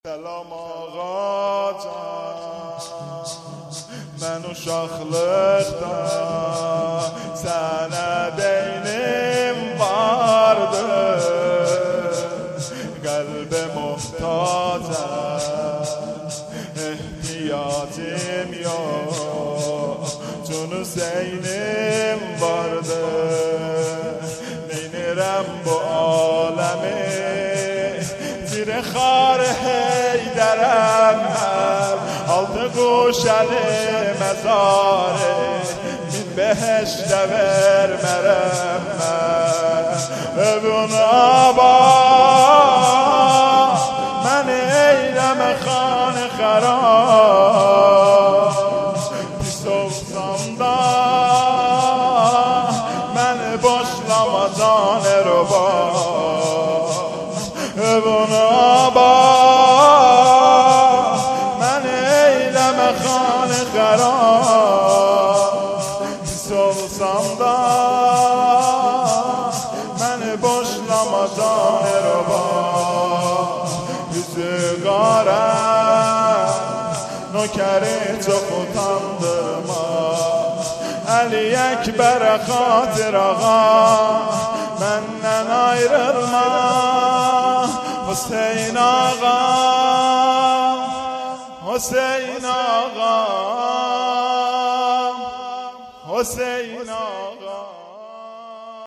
شور ترکی|سلام آقا جان